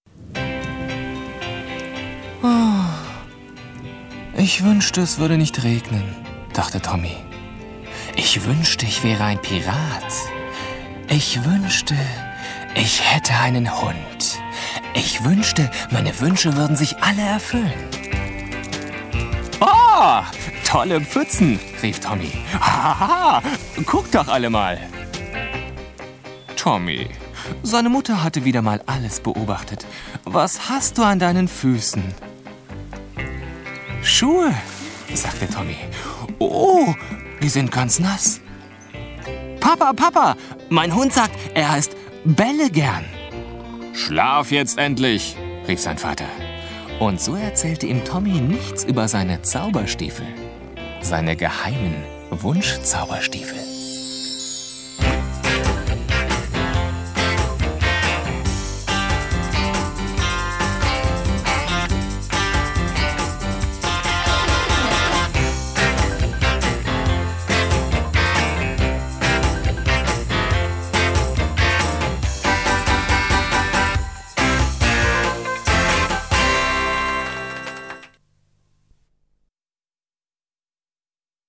jung-dynamische Werbestimme
Kein Dialekt
Sprechprobe: Industrie (Muttersprache):
german young voice over artist